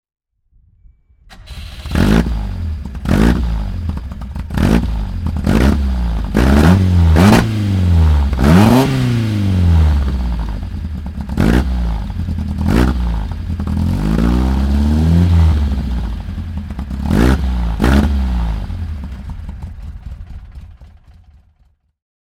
Motorsounds und Tonaufnahmen zu Abarth Fahrzeugen (zufällige Auswahl)
Fiat Abarth 124 Rally (1973) - Starten und Leerlauf